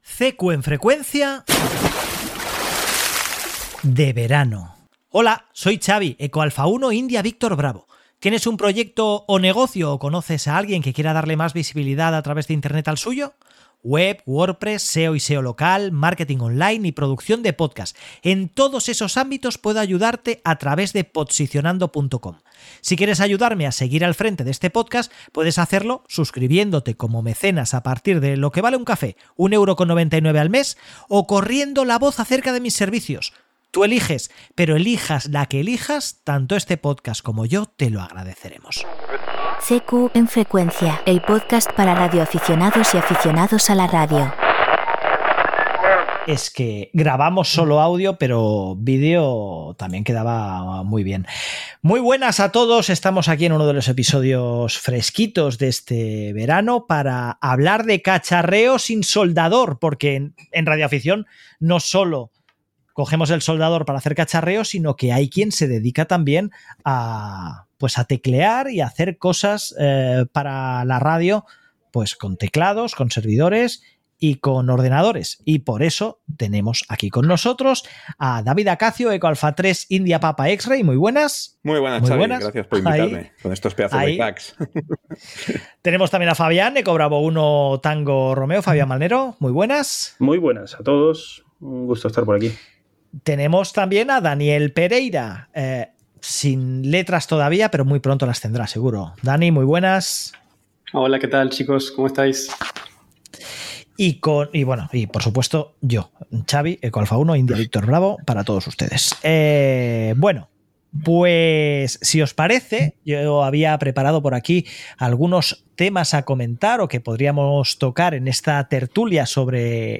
EP76 V4 – Tertulia sobre «Cacharreo Digital» (Sin soldador): SDR, Soft…